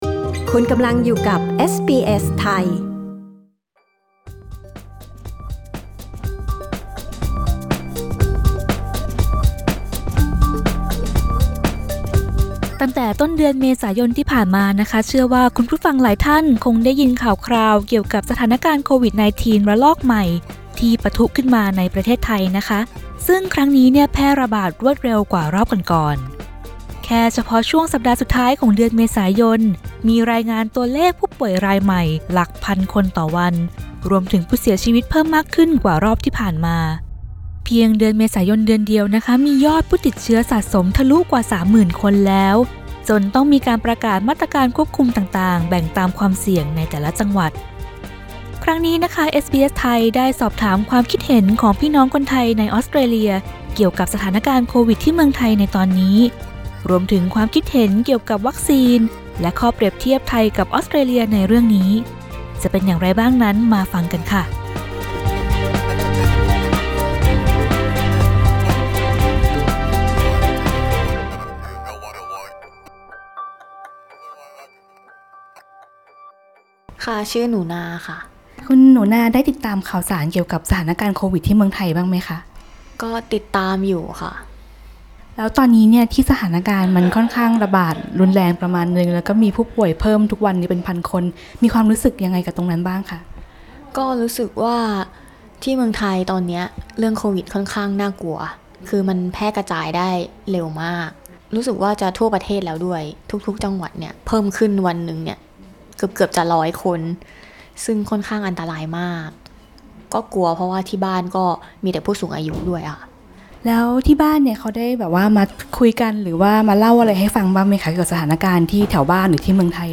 สถานการณ์โควิด-19 ระลอกใหม่ที่ปะทุขึ้นในประเทศไทยตั้งแต่ช่วงต้นเดือนเมษายนแพร่ระบาดรวดเร็วยิ่งกว่าครั้งที่ผ่านมา เอสบีเอส ไทย สอบถามความคิดเห็นของคนไทยในออสเตรเลียเกี่ยวกับเรื่องนี้ รวมถึงประเด็นวัคซีนและข้อเปรียบเทียบระหว่างสองประเทศ